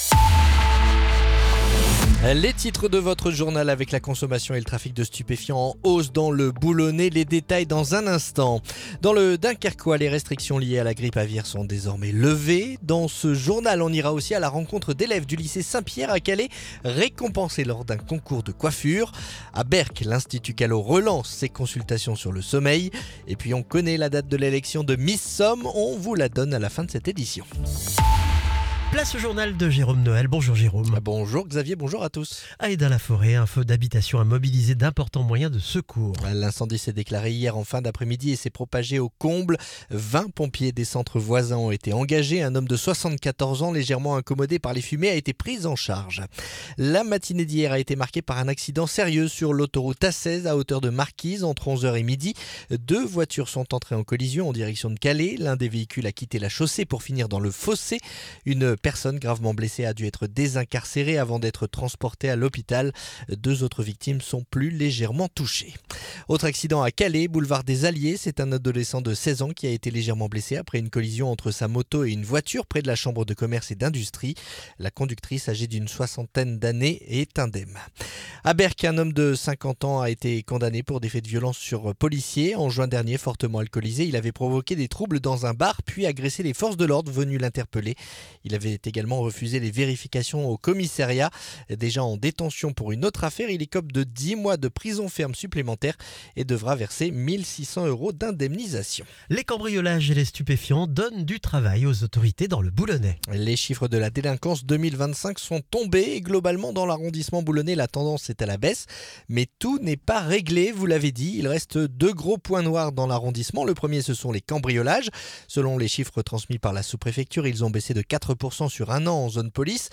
Le journal du mardi 17 février